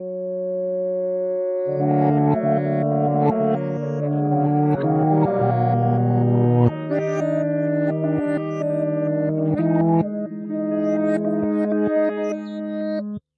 描述：a drone produced from heavily processed recording of a human voice
标签： drone processed voice
声道立体声